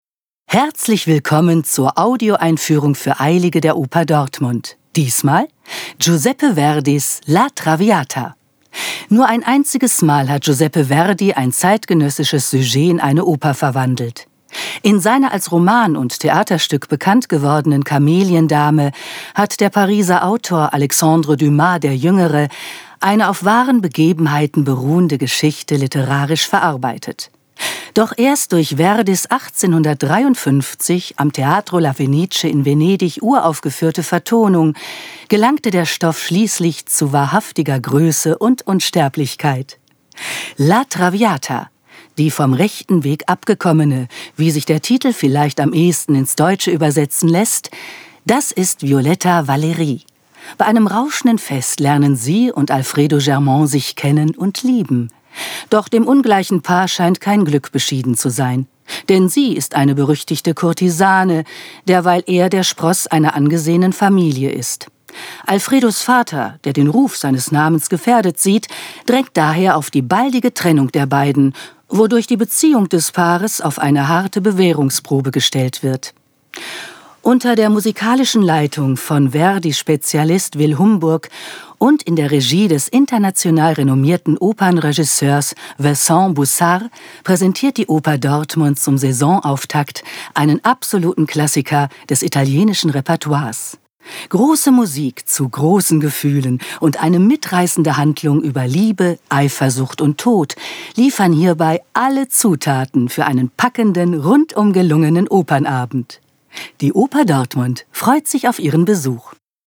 tdo_Audioeinfuehrung_La_Traviata.mp3